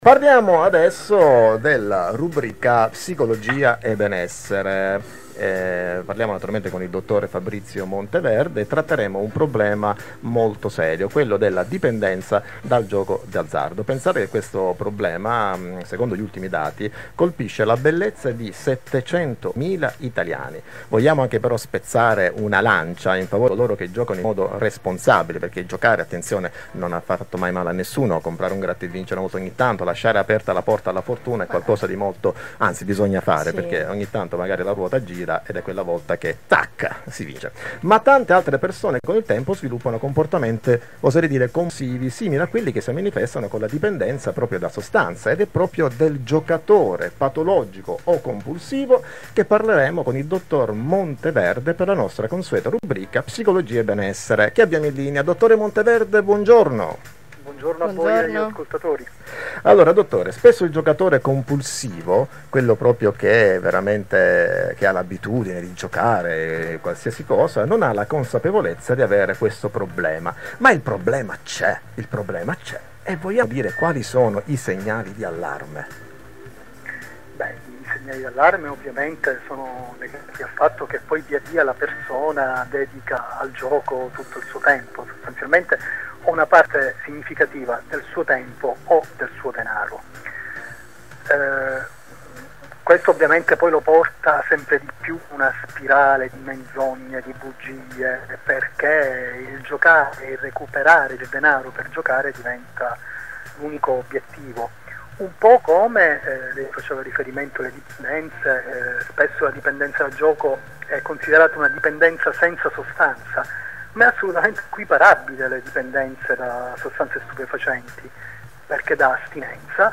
Intervista radiofonica sulla dipendenza dal gioco